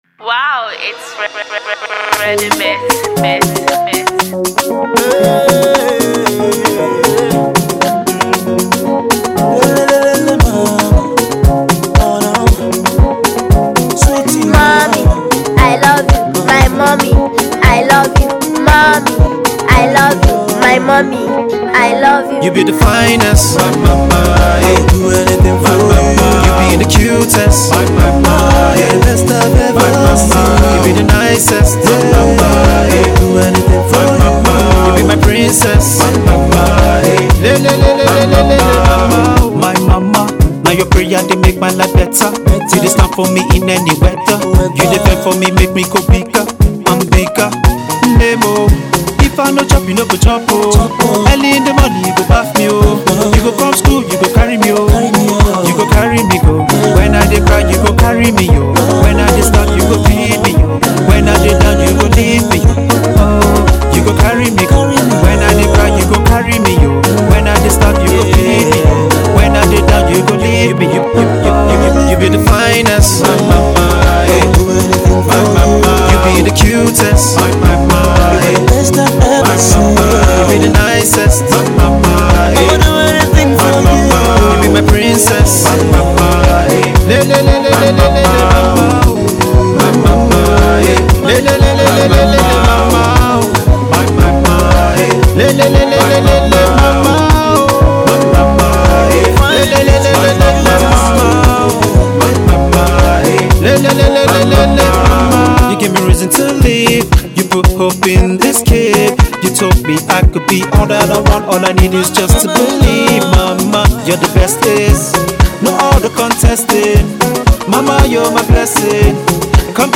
Pop
fresh blend of Afrobeat and Hip Hop